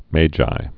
(mājī)